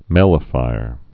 (mĕlə-fīr)